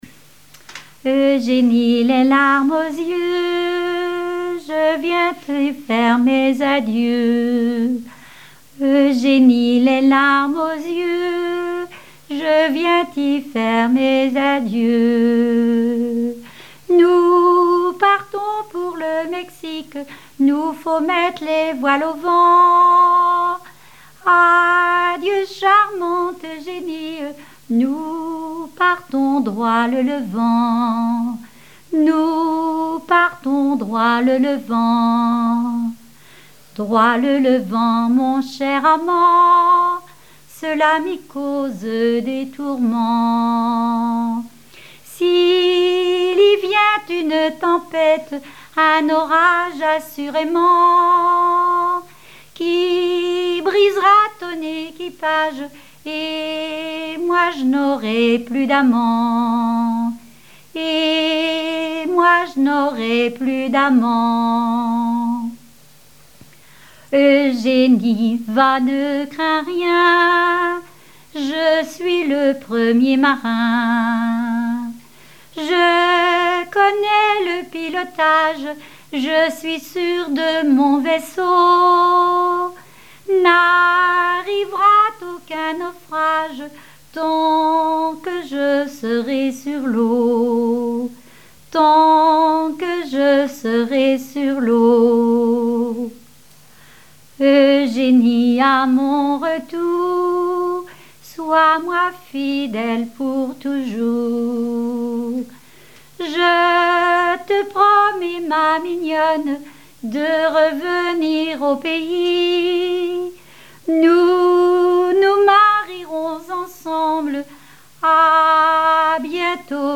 répertoire de chansons populaires
Pièce musicale inédite